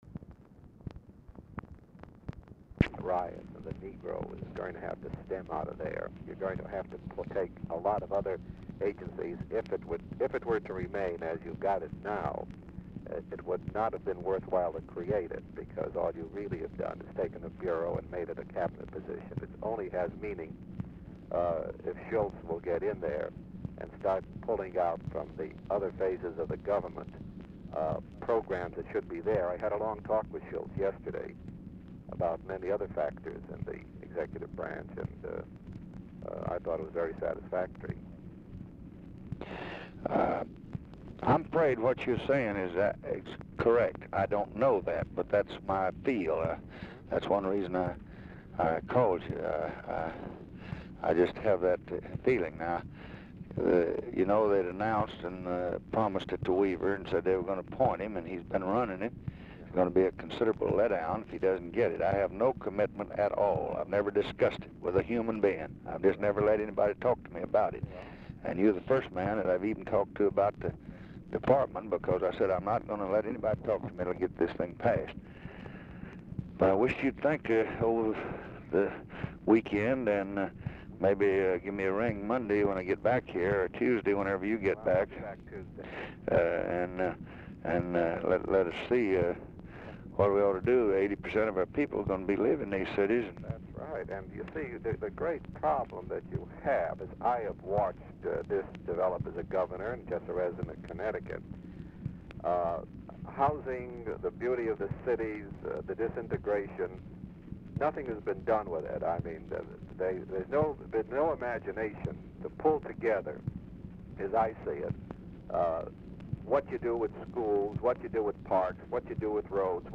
Telephone conversation
RECORDING STARTS AFTER CONVERSATION HAS BEGUN; CONTINUES ON NEXT RECORDING
Dictation belt
Mansion, White House, Washington, DC